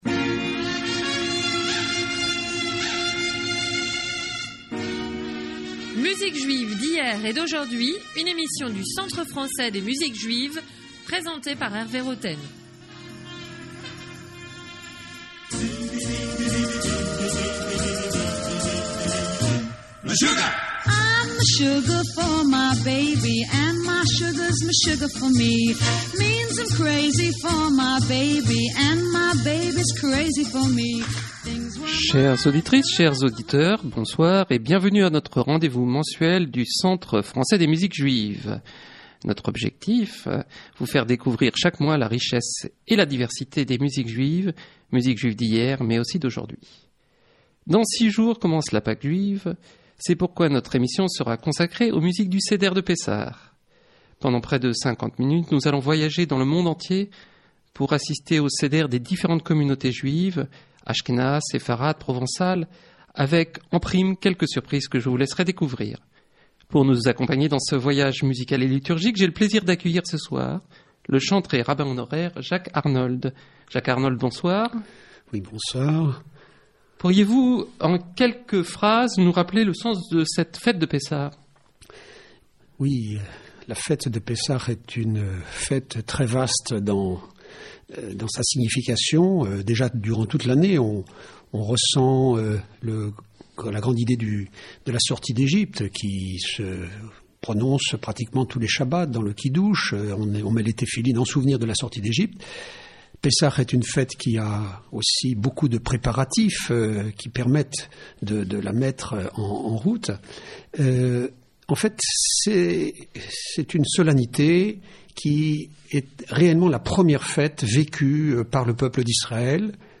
Radio program in French